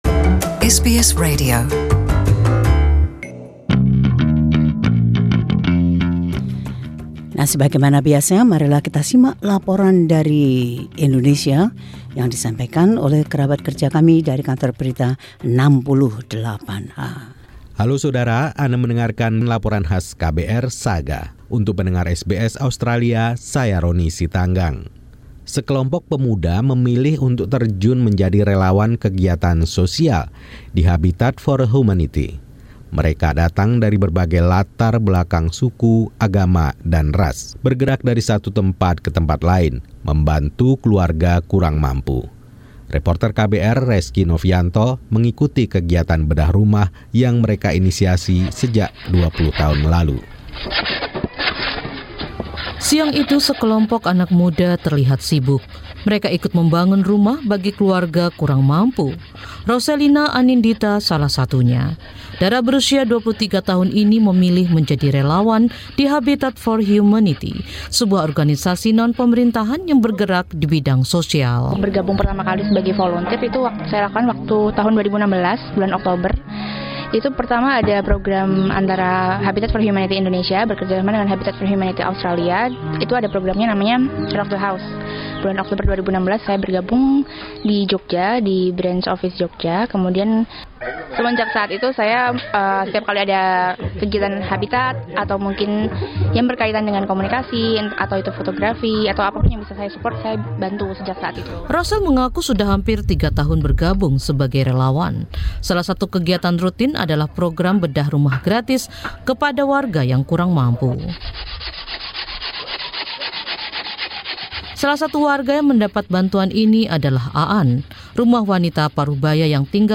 Tim KBR 68H melaporkan cara kerja serta capaian-capaian dari organisasi amal ini beserta para relawannya.